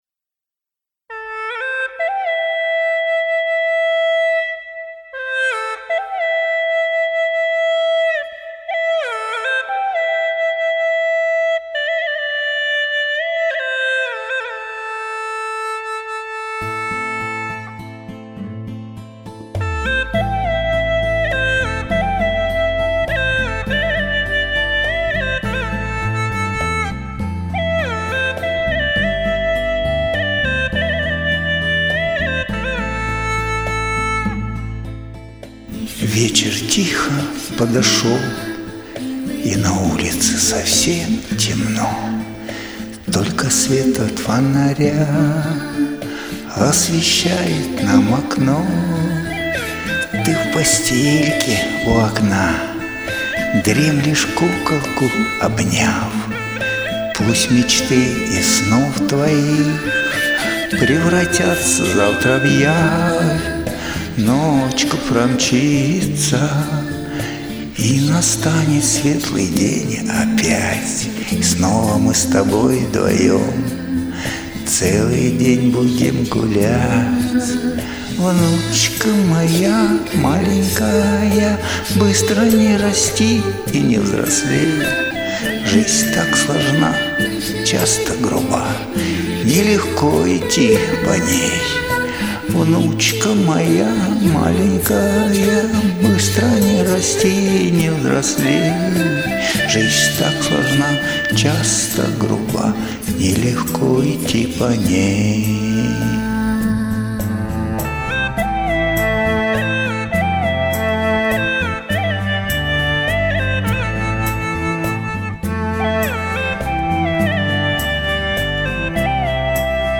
Трогательная колыбельная получилась классно придумано !